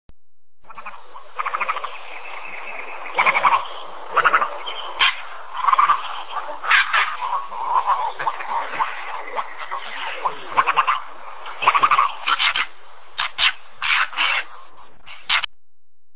Aigrette Garzette
(Egretta garzetta)
aigrette.wma